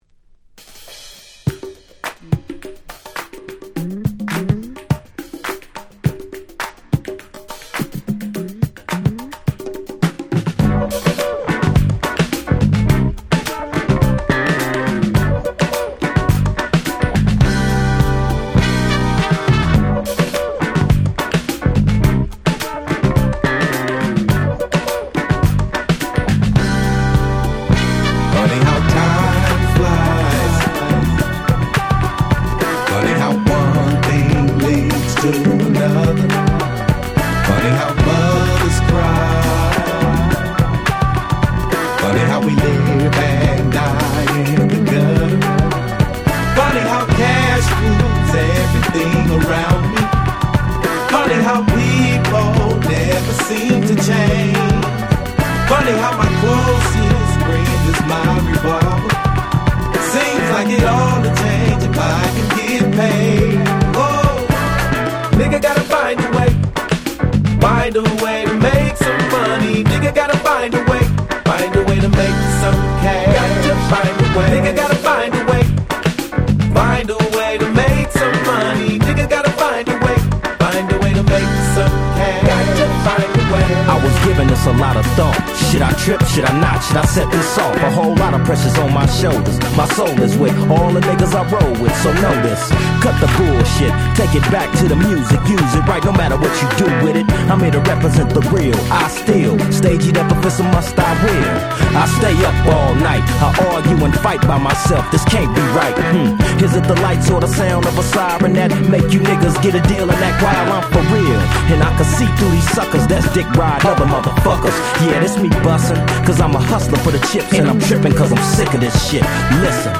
Gangsta Rap